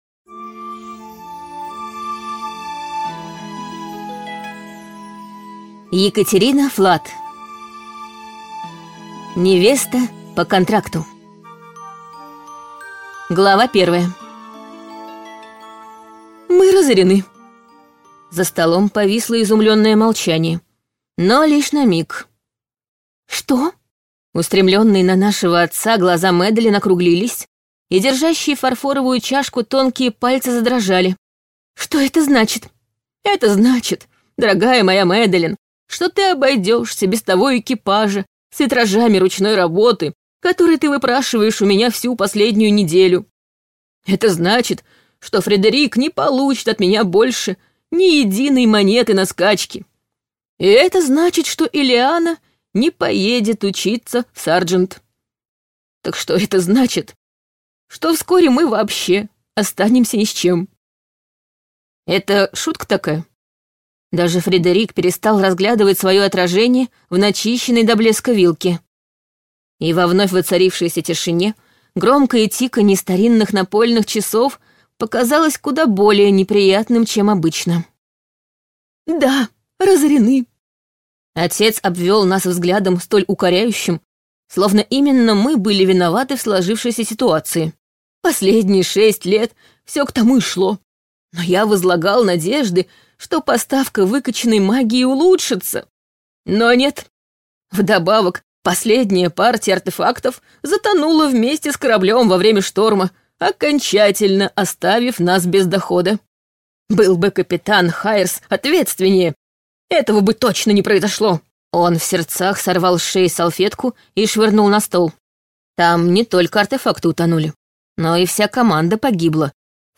Аудиокнига Невеста по контракту | Библиотека аудиокниг
Прослушать и бесплатно скачать фрагмент аудиокниги